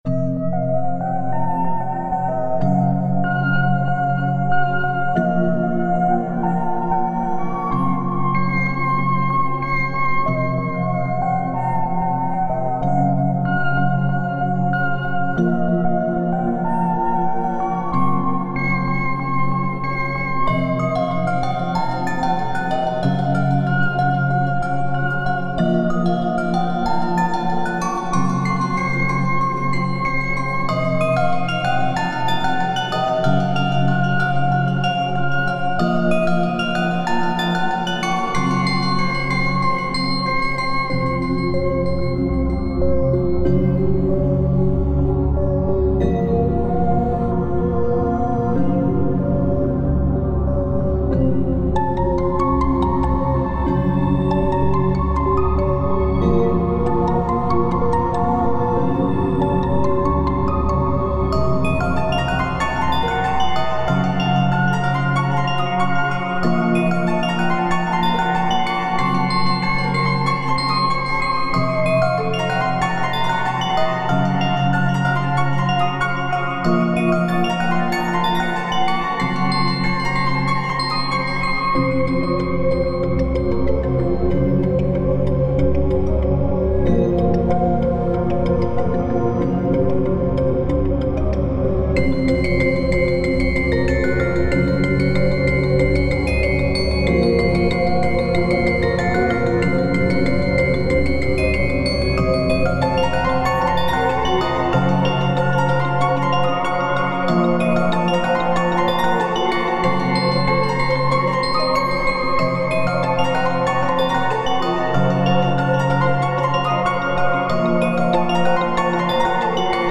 幻想的で不気味な雰囲気の海の楽曲で、ハーモニックマイナーの第二モードを意識して作曲している。